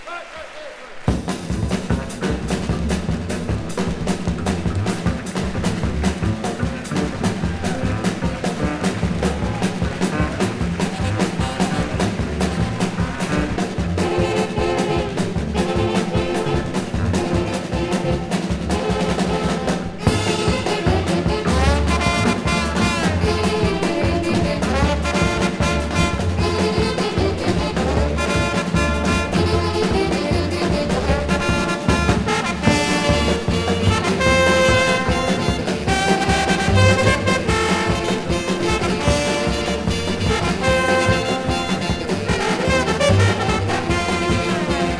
Jazz, Free Jazz　Netherlands　12inchレコード　33rpm　Stereo